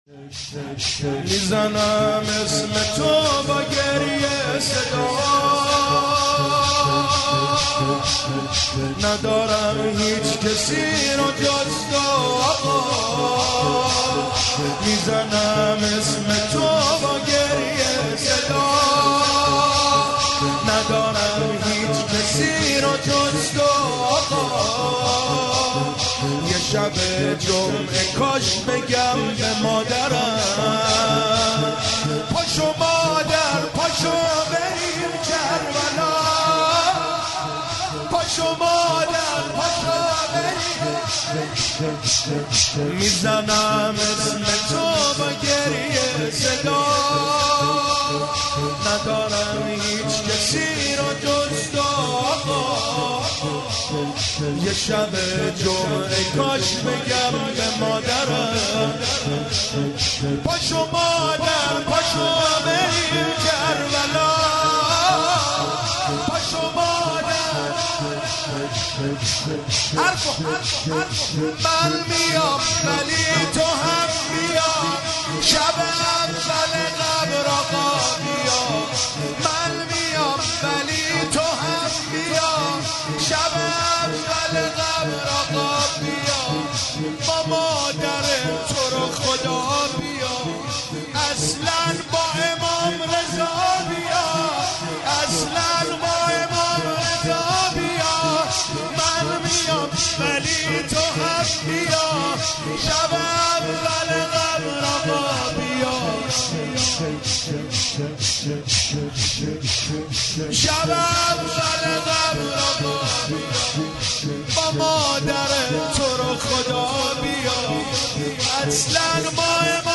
مداحی و نوحه
سینه زنی، شهادت حضرت فاطمه زهرا(س